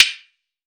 TC3Perc6.wav